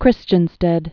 (krĭschən-stĕd)